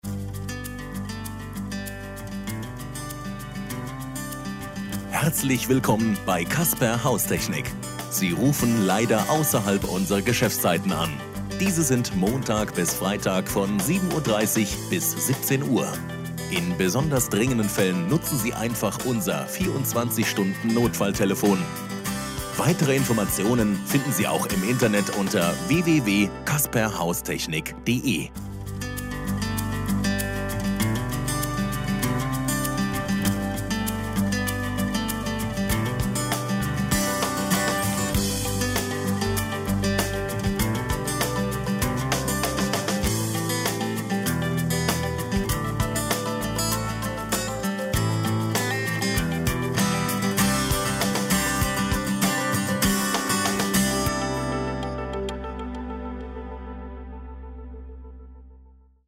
Produzierte Ansage in der Telefonanlage: